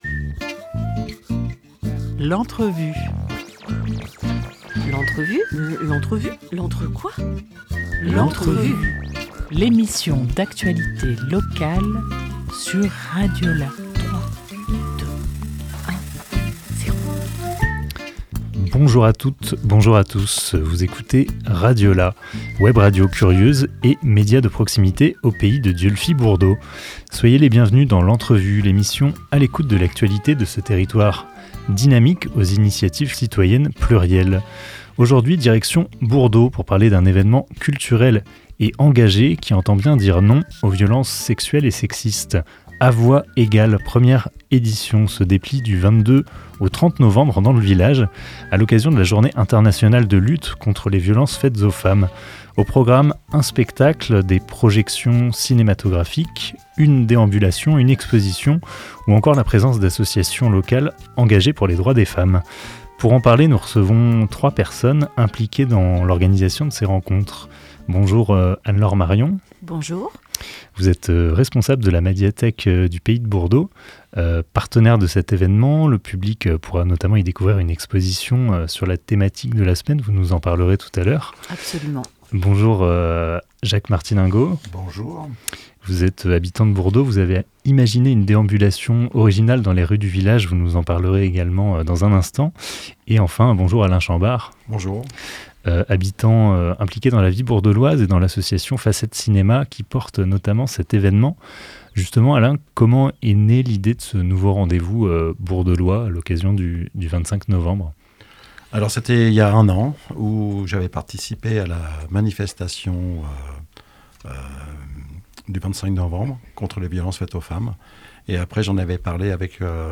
21 novembre 2024 10:44 | Interview